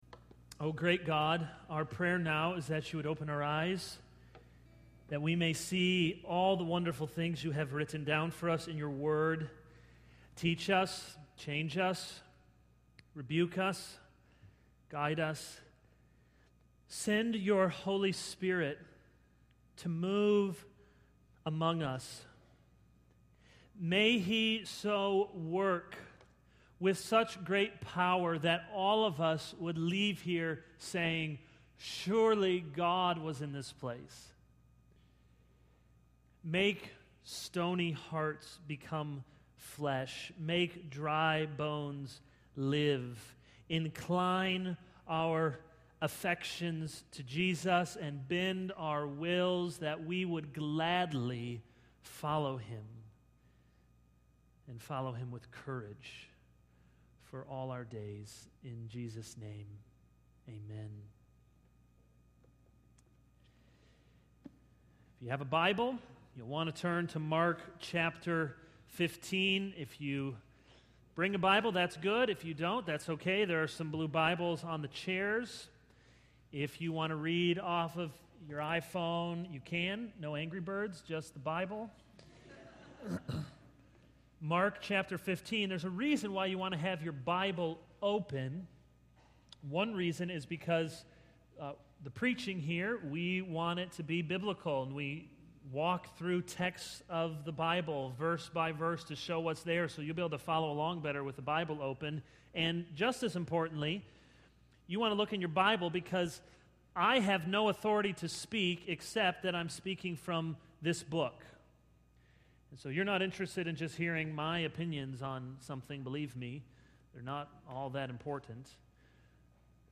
All Sermons A Royal Coward 0:00 / Download Copied!